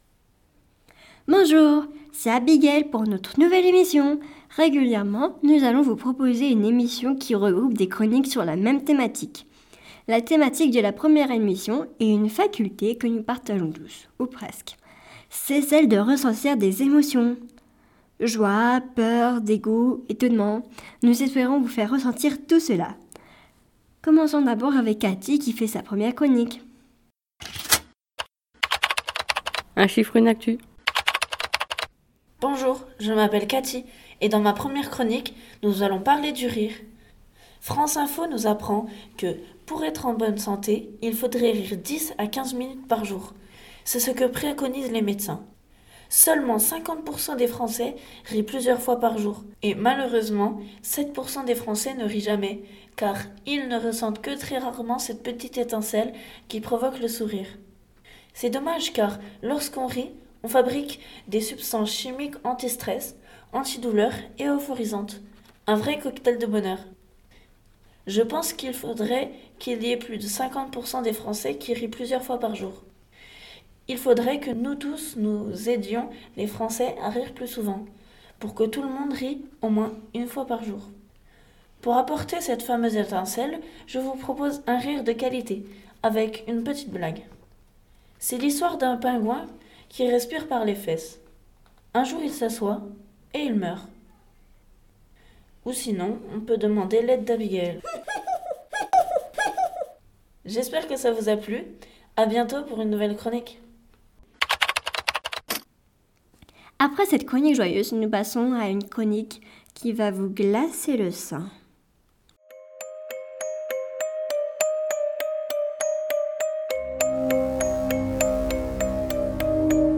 Bruitages : UniversalSoundbank